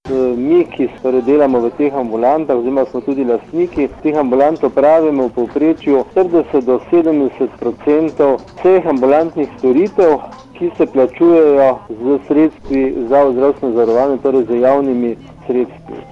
Povzetek objave na Radiu 1